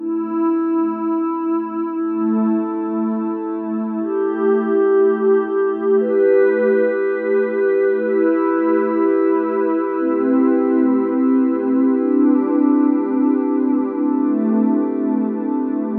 ambient_dream.wav